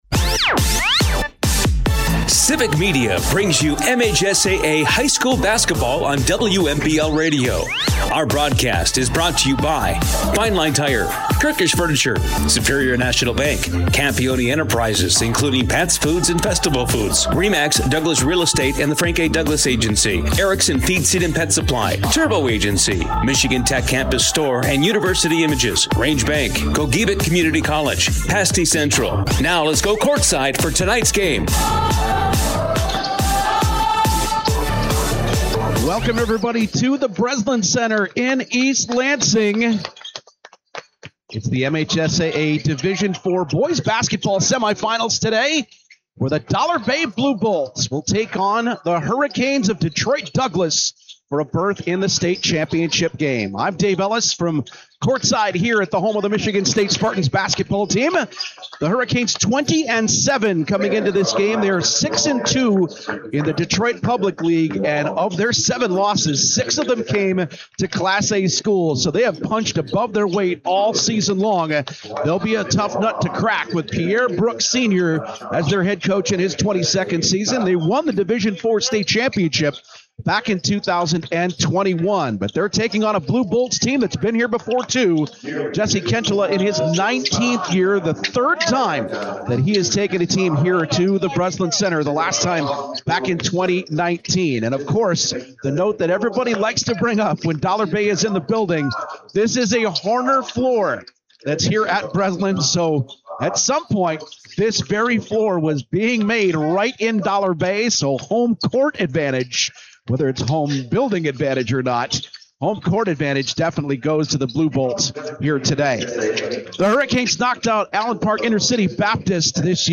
wkmj sports